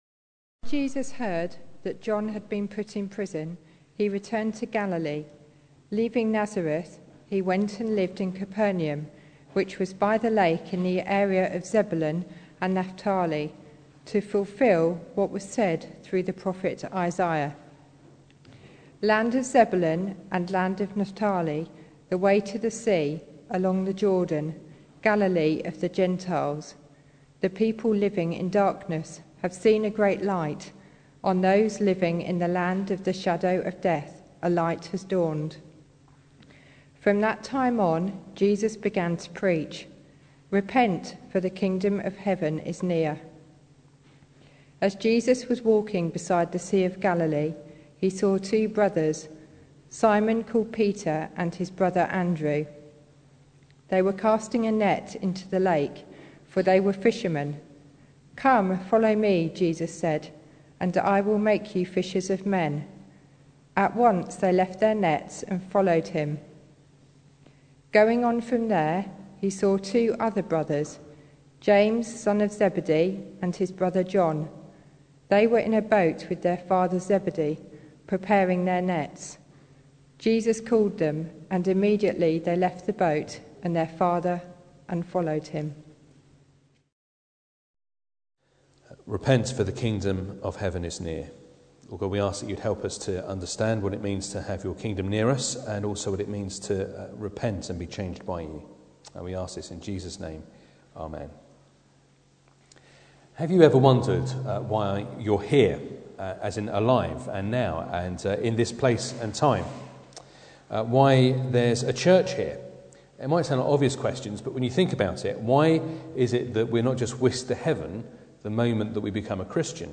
Passage: Matthew 4:12-25 Service Type: Sunday Morning